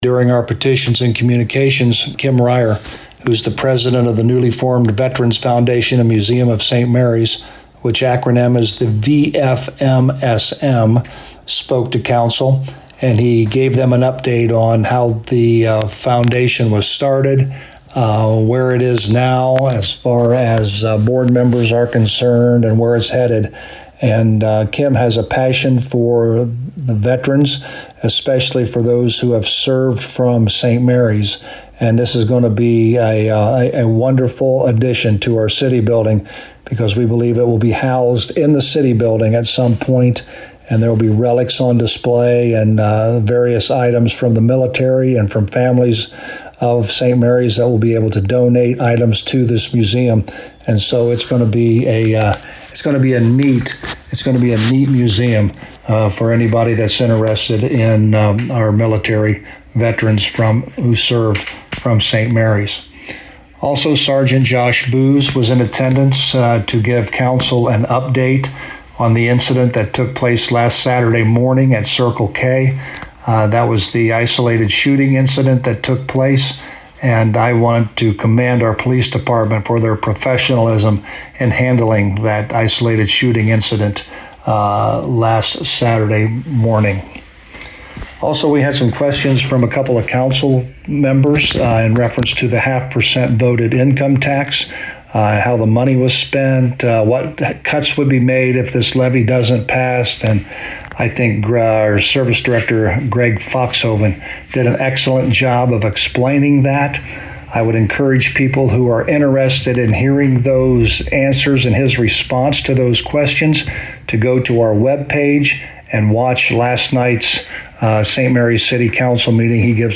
To hear a summary of the Monday April 28th Council Meeting with St Marys Mayor Joe Hurlburt: